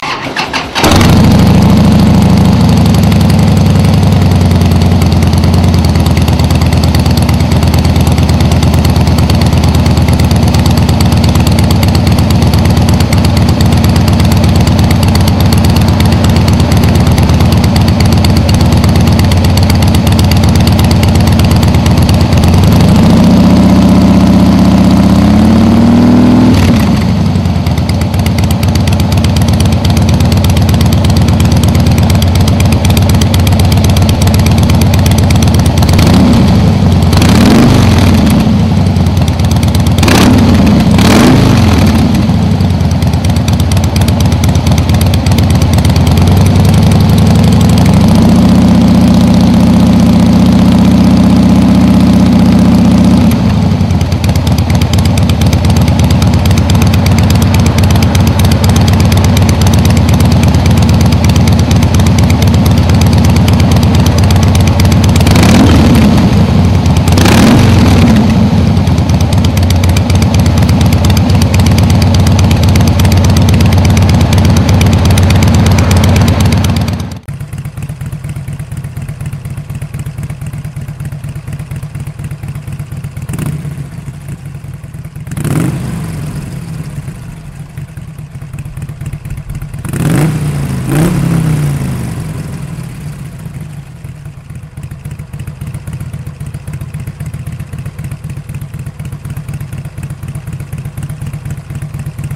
Catégorie 2 : Bruit plus raisonnable +++
Leur niveau sonore est proche de celui des Screamin’ Eagle®.
S&S-Tapered_Slip-on_Mufflers.mp3